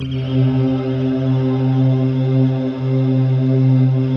Index of /90_sSampleCDs/Optical Media International - Sonic Images Library/SI1_RainstickChr/SI1_RainstickMix